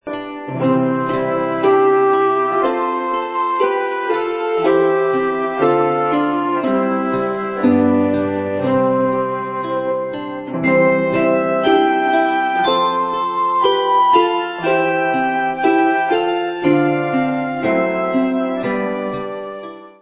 hudba